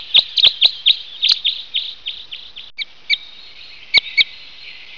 Red Crossbill
Red-Crossbill.mp3